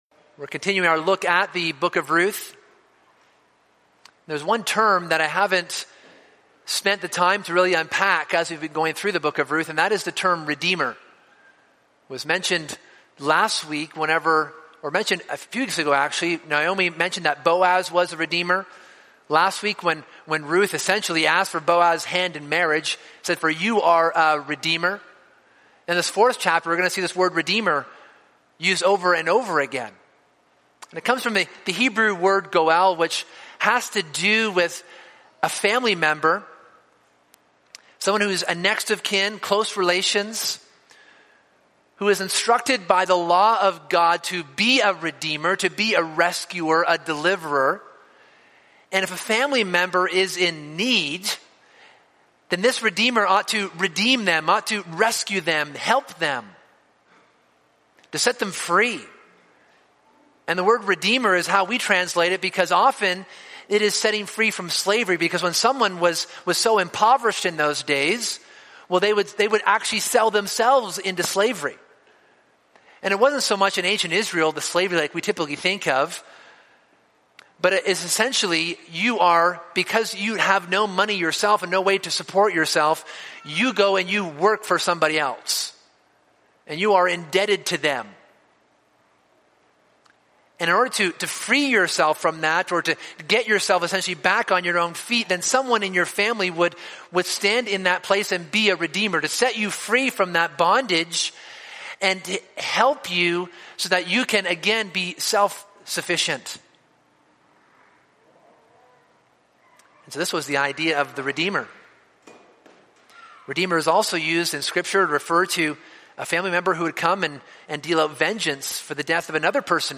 Continuing through the story of Boaz and Ruth this sermon examines the scene when Boaz is before the elders of the city to discuss and decide who will redeem Ruth and Naomi.